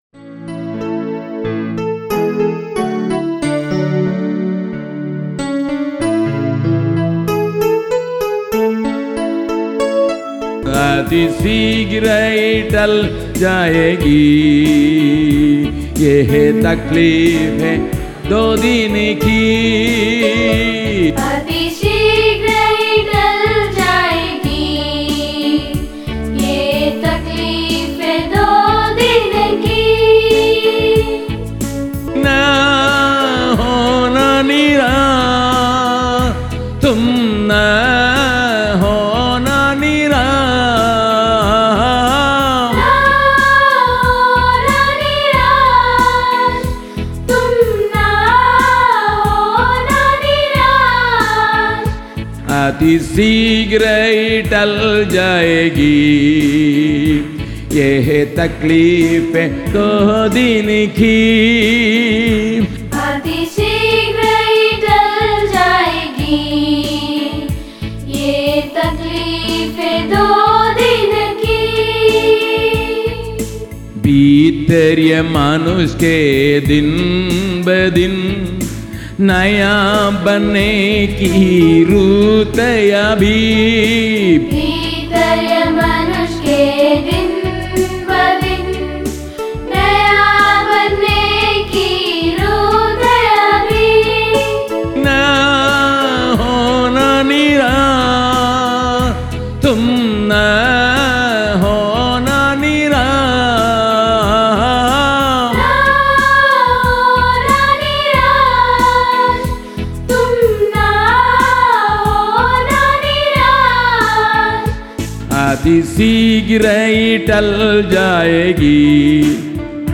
Royalty-Free Gospel songs